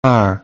us_phonetics_sound_card_2023feb.mp3